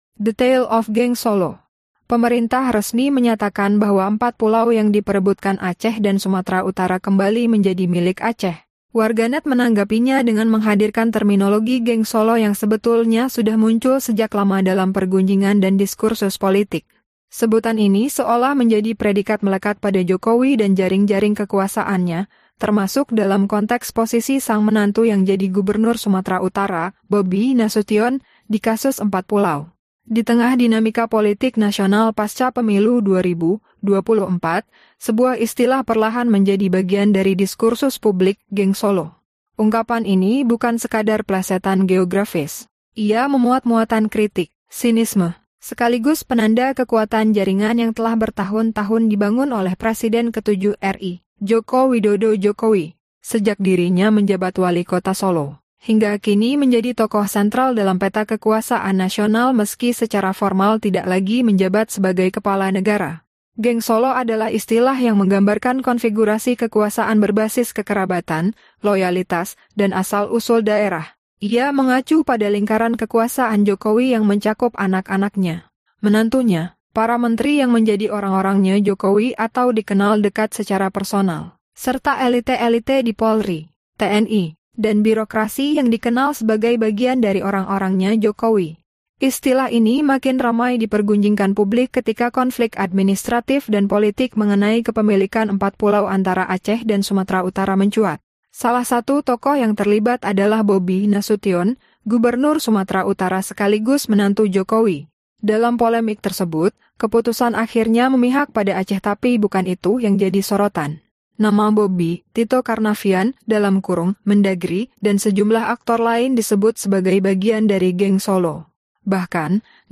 El audio se realiza usando AI.